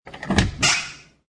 descargar sonido mp3 puerta 10